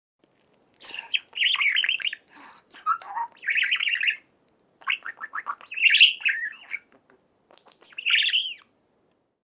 دانلود صدای مرغ عشق برای فیلم و ساخت کلیپ از ساعد نیوز با لینک مستقیم و کیفیت بالا
جلوه های صوتی